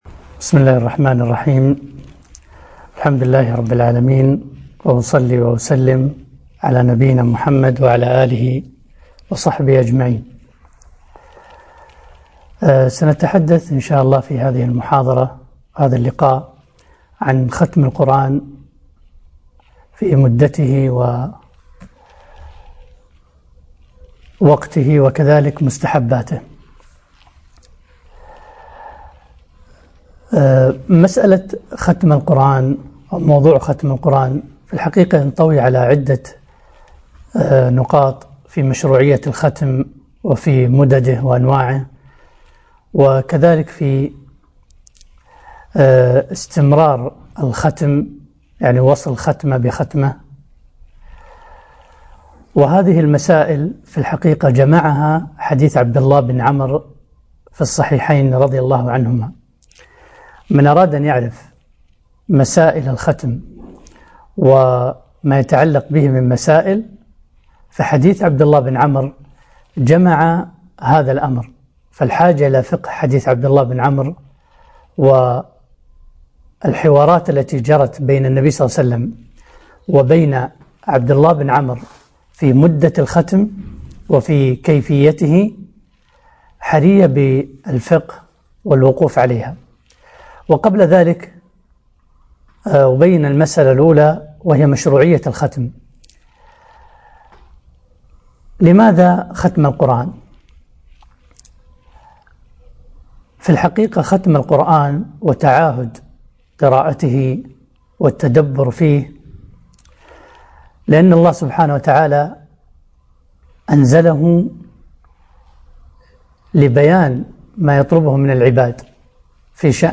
محاضرة - ختم القرآن مداته ووقته ومستحباته